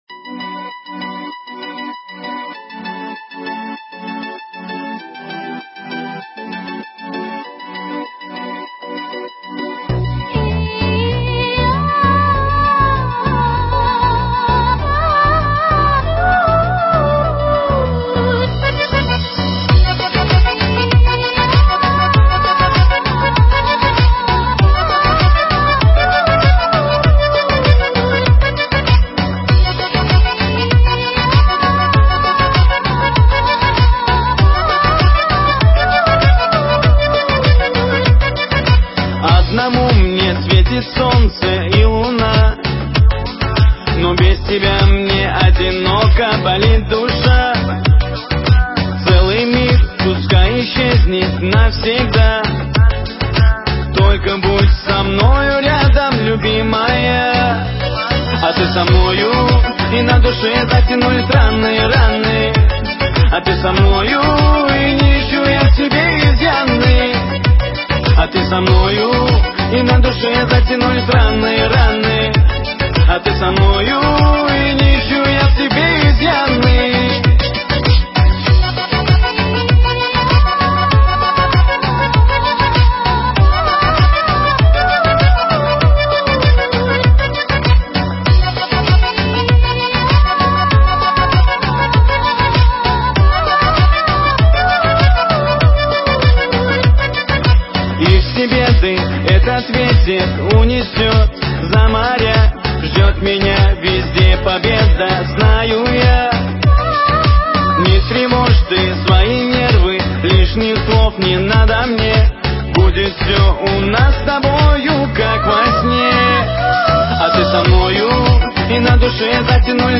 попса